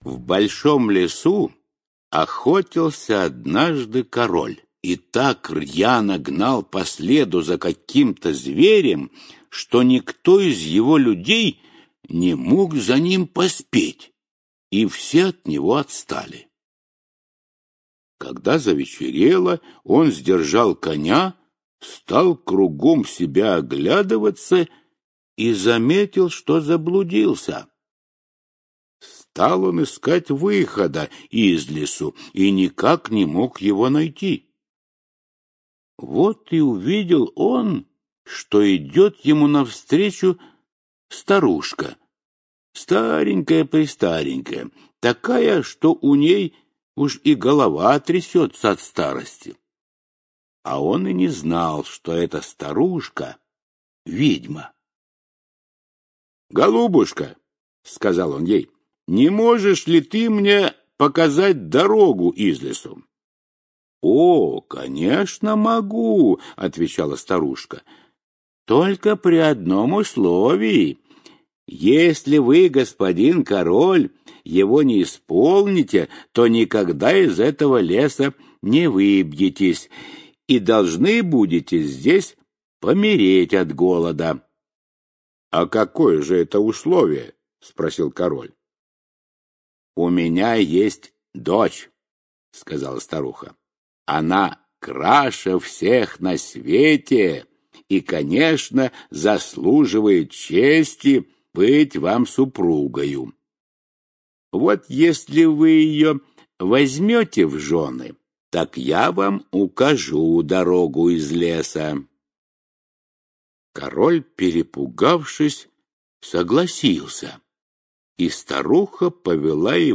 Шесть лебедей - аудиосказка братьев Гримм. Мачеха заколдовала шесть братьев, превратив их в лебедей. Только сестра могла их расколдовать.